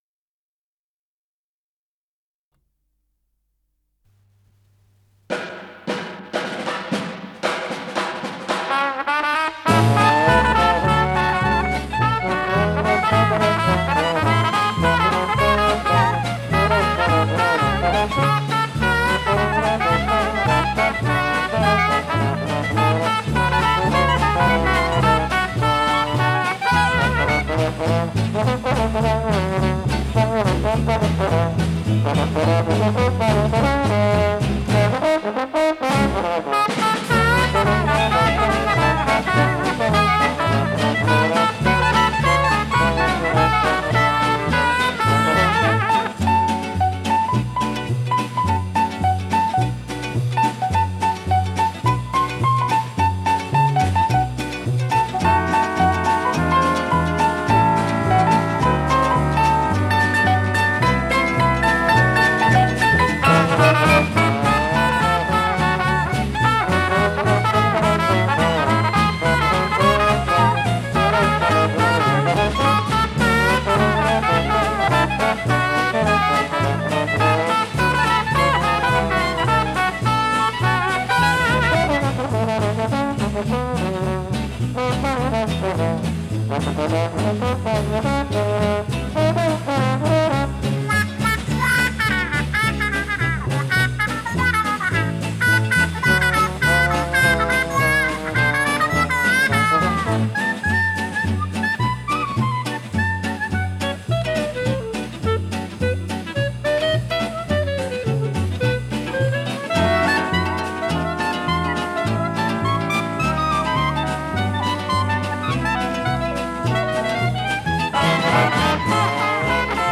АккомпаниментИнструментальный ансамбль
ВариантДубль моно